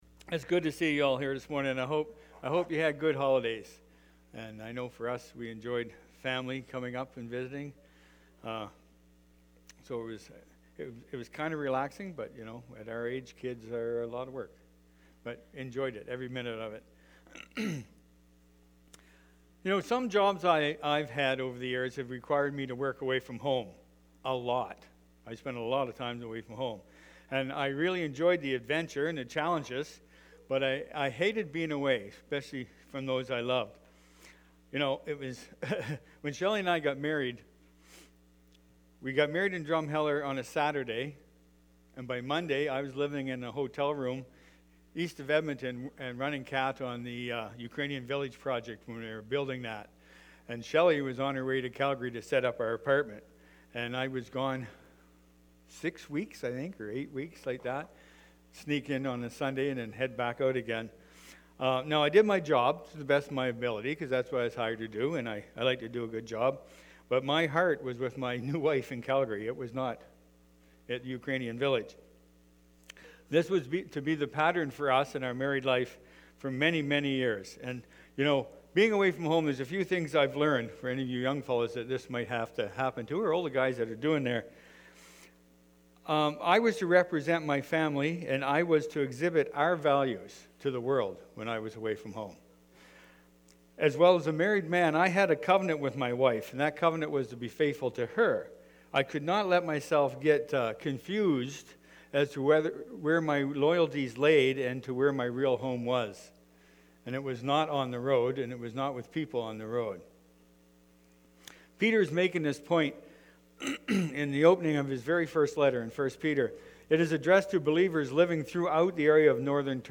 Sermon-audio-Jan-1-2023.mp3